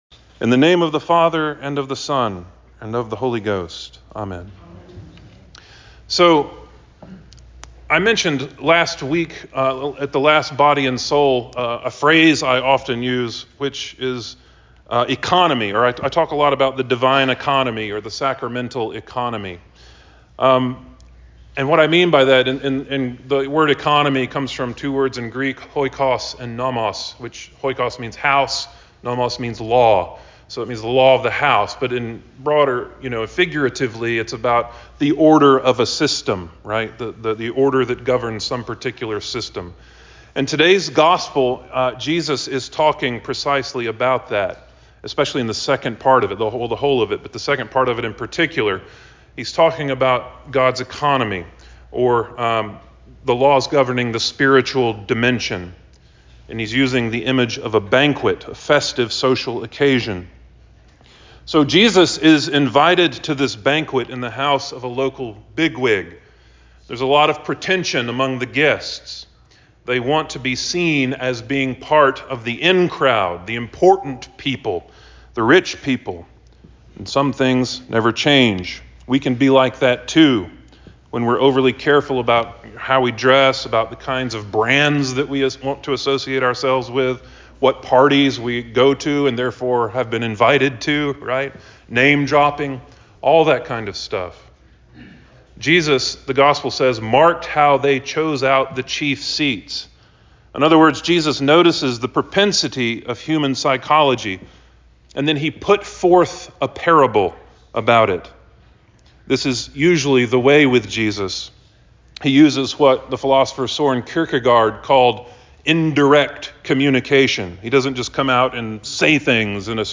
Trinity 17 Sermon 10.01.23